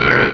Cri de Spoink dans Pokémon Rubis et Saphir.